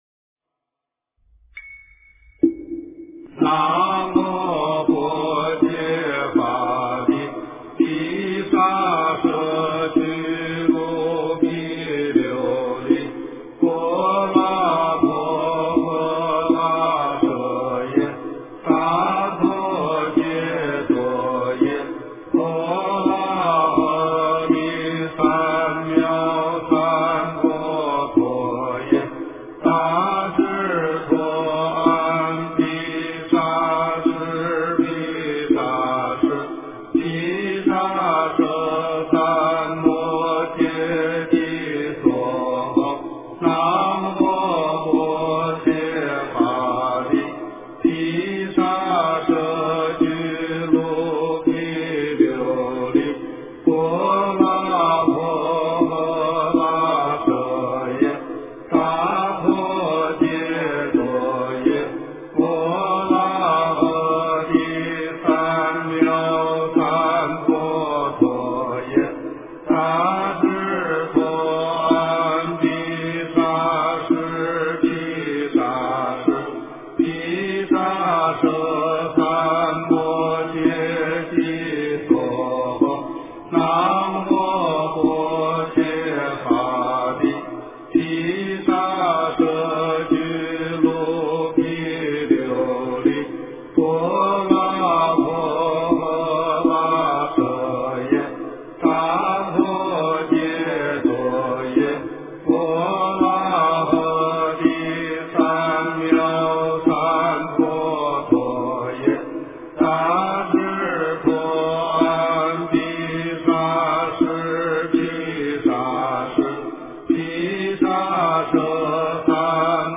经忏
佛音 经忏 佛教音乐 返回列表 上一篇： 赞佛偈--寺院 下一篇： 晚课--深圳弘法寺 相关文章 饶益一切天人--巴利文佛教歌曲 饶益一切天人--巴利文佛教歌曲...